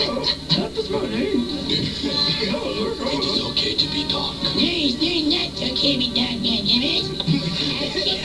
Star Wars And South Park have gone to the Backstreets (A.J is Cartman, Kevin Is the other dude)